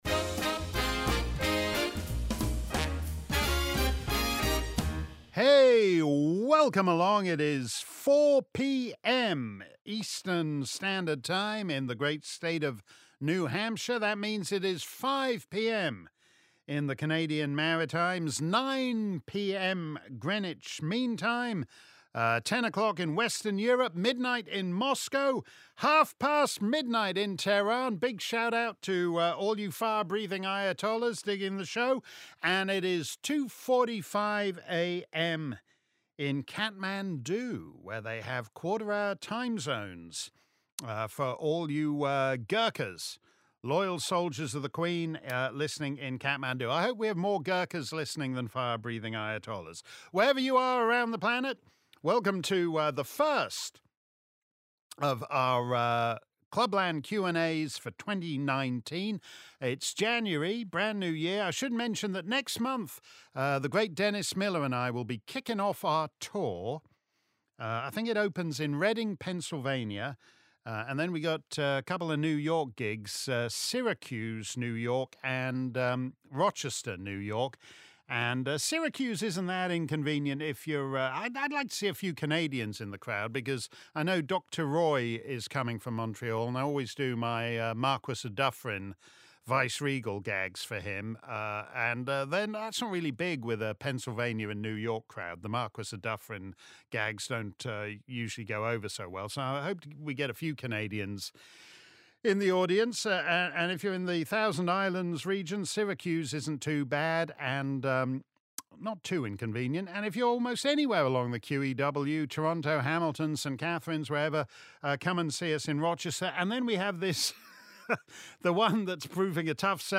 If you missed our livestream Clubland Q&A, here's the action replay. Simply click above and settle back for an hour of my answers to questions from Mark Steyn Club members around the planet.